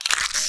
rifle_sniper_mode_off.wav